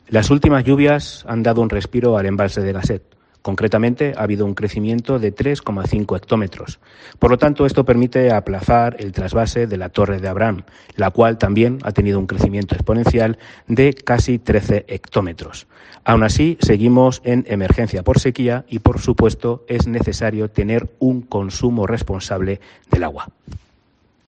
Guillermo Arroyo, portavoz del Ayuntamiento de Ciudad Real